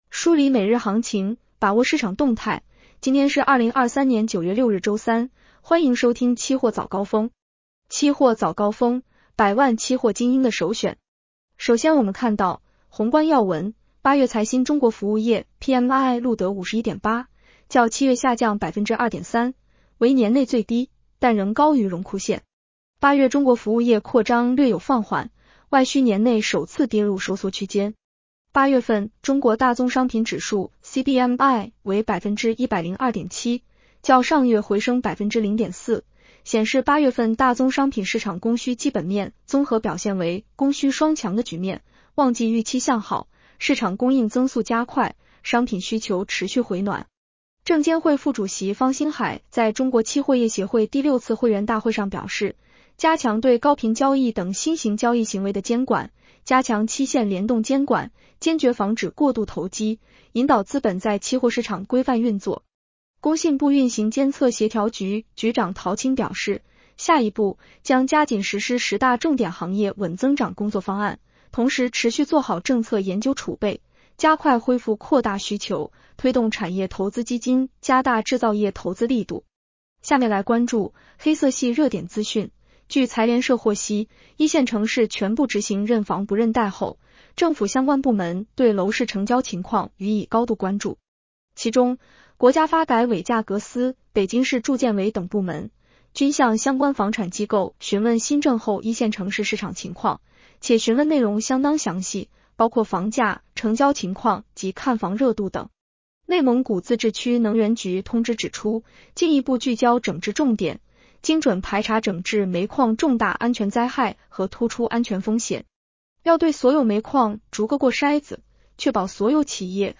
【期货早高峰-音频版】 女声普通话版 下载mp3 宏观要闻 1. 8月财新中国通用服务业经营活动指数（服务业PMI）录得51.8，较7月下降2.3个百分点， 为年内最低，但仍高于荣枯线 。